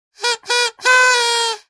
Звуки праздничной дудки